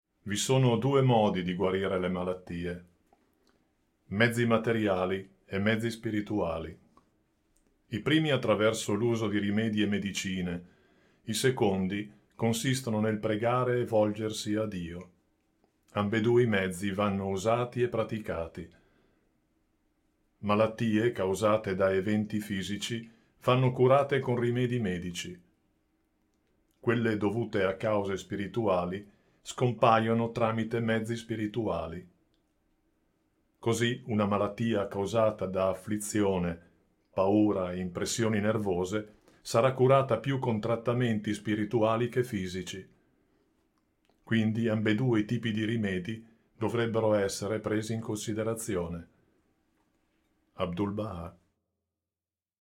Audiolibri Bahá'í Gratis